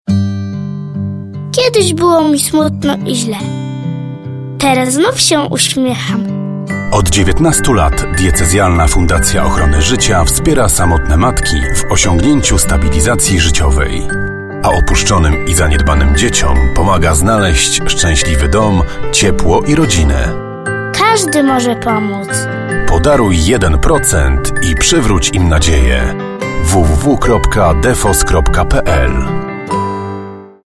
spot radiowy